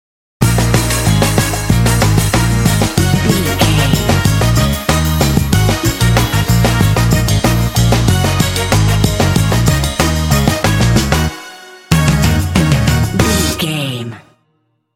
This cool and funky track is great for action games.
Aeolian/Minor
E♭
groovy
driving
saxophone
drums
percussion
bass guitar
electric guitar